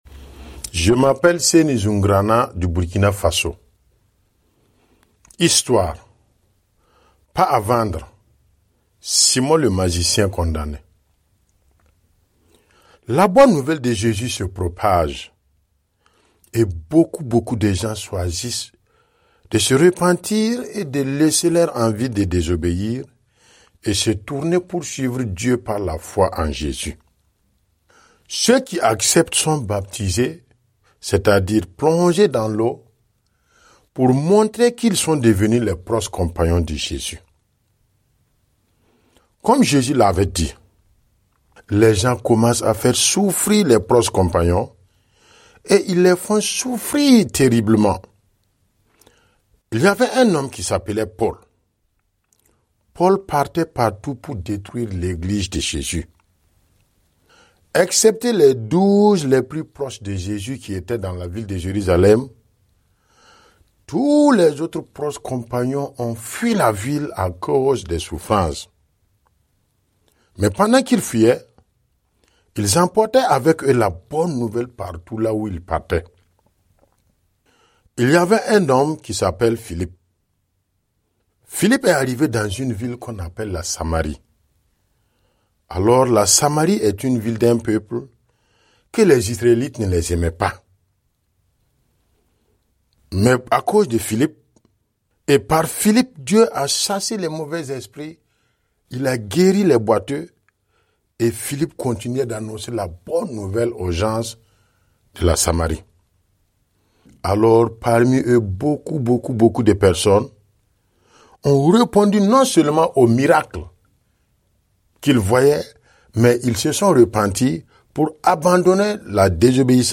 raconter l'histoire du magicien qui a essayé de manipuler la puissance de Dieu.